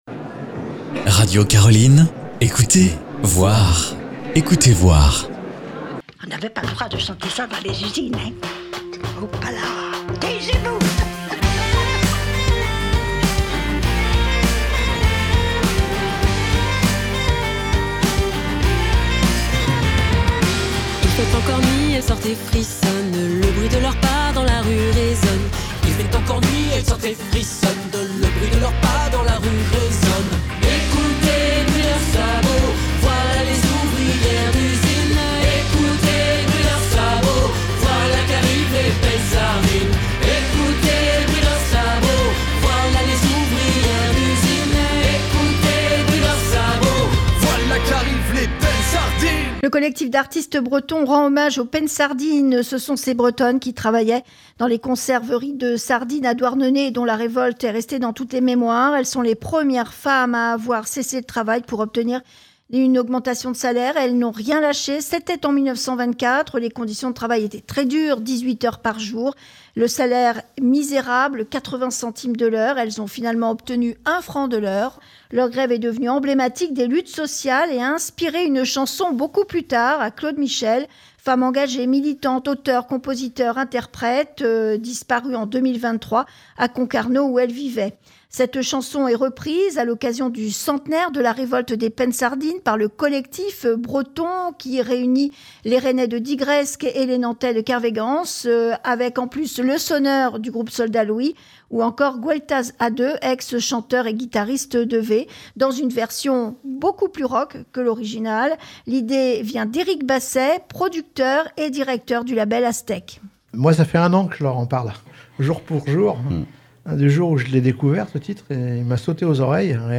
Invités d'Ecouter Voir sur Radio Caroline, vous pouvez les retrouver en replay.